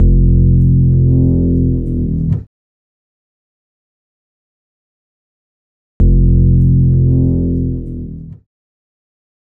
Astro 2 Bass-F.wav